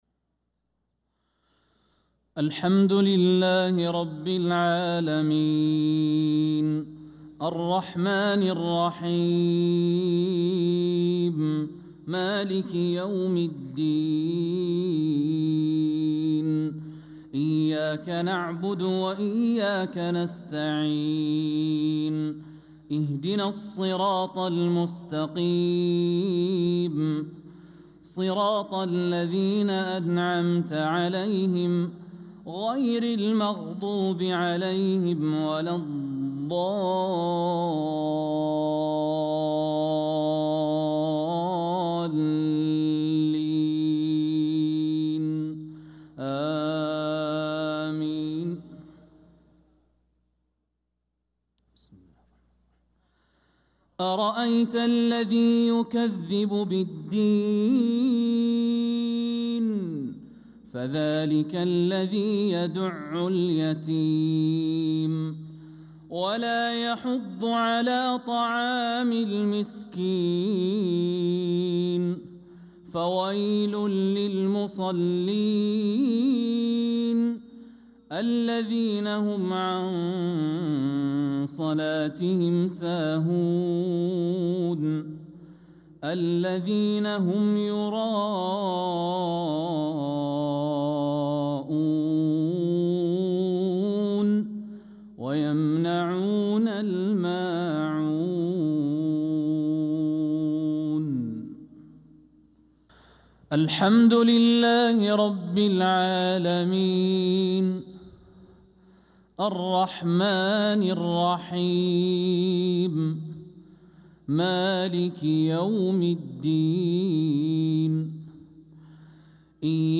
صلاة المغرب للقارئ الوليد الشمسان 30 ربيع الآخر 1446 هـ
تِلَاوَات الْحَرَمَيْن .